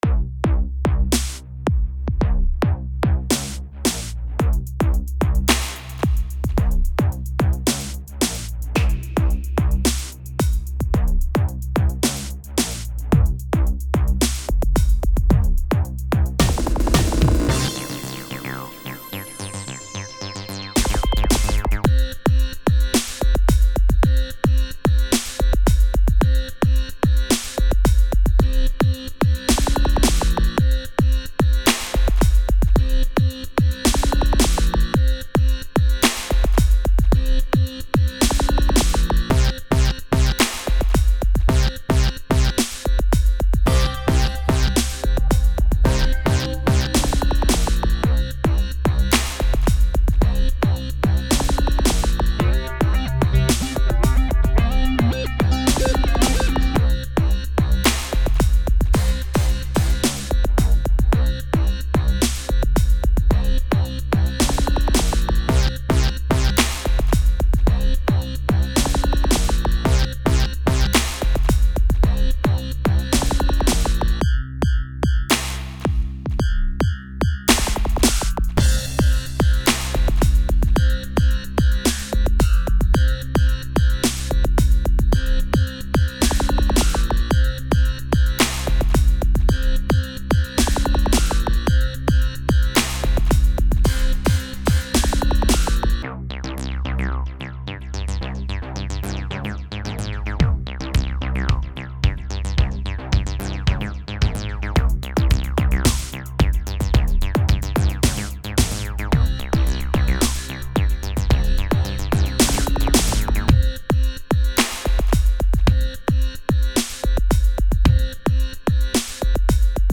Song 5 After Mastering